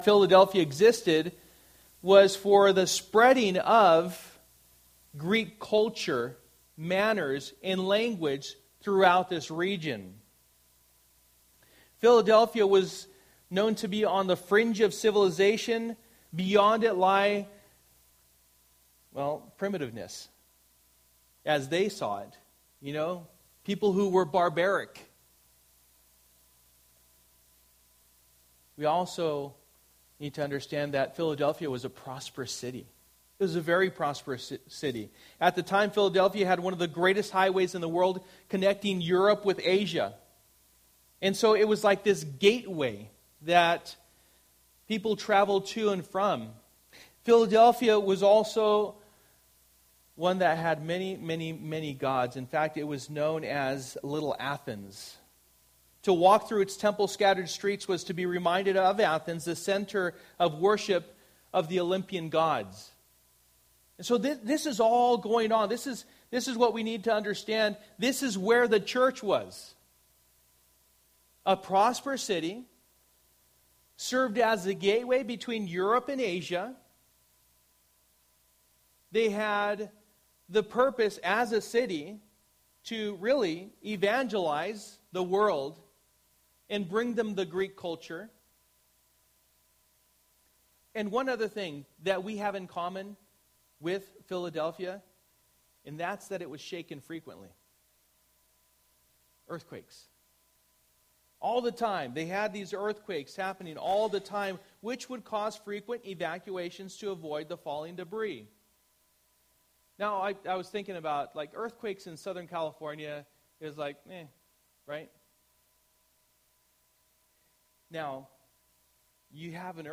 Passage: Revelation 3:7-13 Service: Sunday Morning